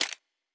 pgs/Assets/Audio/Sci-Fi Sounds/Weapons/Weapon 02 Foley 1.wav at 7452e70b8c5ad2f7daae623e1a952eb18c9caab4
Weapon 02 Foley 1.wav